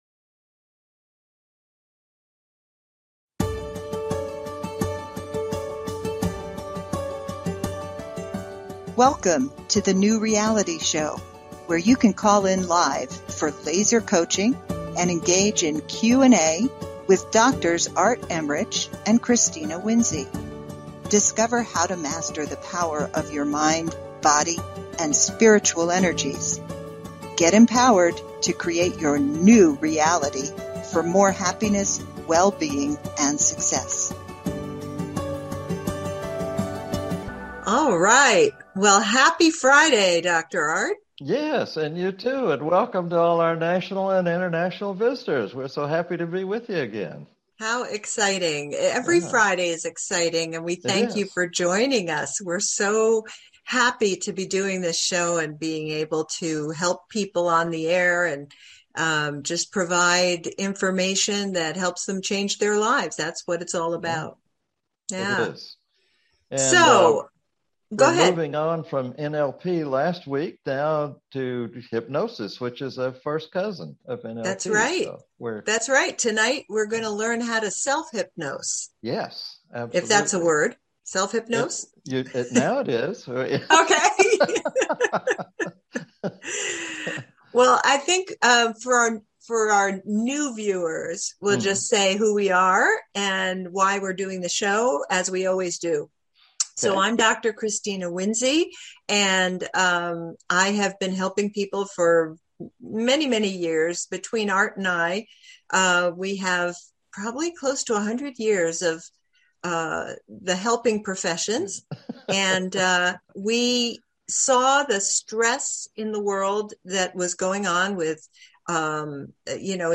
Talk Show Episode, Audio Podcast, What Can You Accomplish with Self-Hypnosis? and Tap Into Your Inner Power on , show guests , about Self-Hypnosis,focused frame of mind,tap into your abilities,your unique power,state of intense focus,state of self-hypnosis,easy to learn,get more motivated,become more self-aware, high performance abilities, categorized as Health & Lifestyle,Kids & Family,Philosophy,Psychology,Emotional Health and Freedom,Mental Health,Self Help,Motivational,Access Consciousness